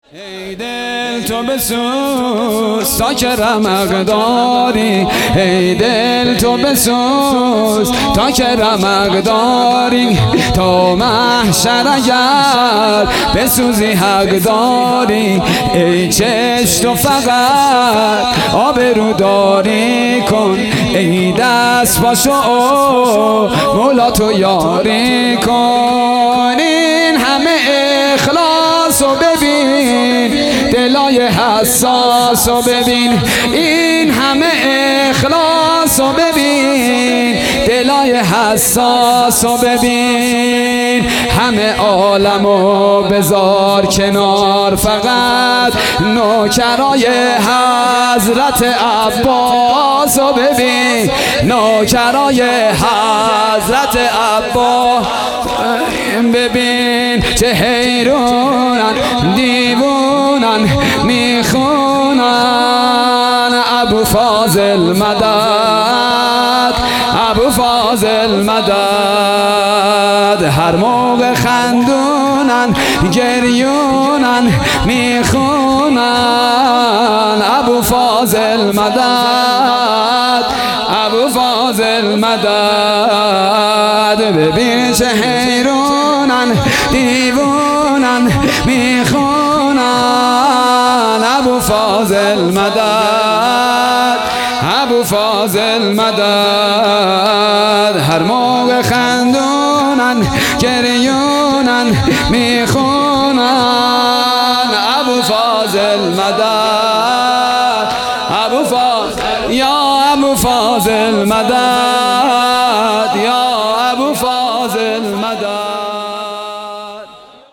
حسینیه بیت النبی - شب نهم محرم الحرام 1443
شور